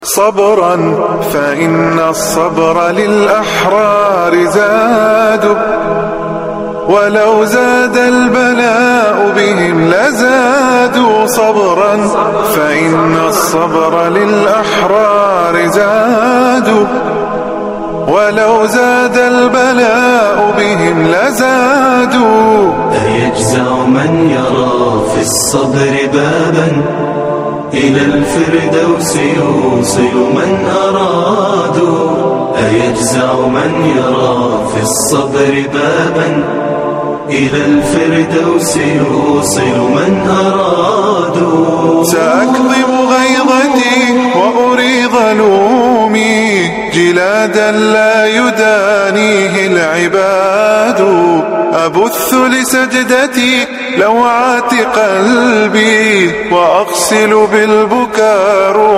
فائدة من الدرس العاشر من دروس تفسير سورة الأنفال والتي ألقيت في رحاب المسجد النبوي حول أن تفسير {إن الله معنا} معية الله للنبي صلى الله عليه وسلم.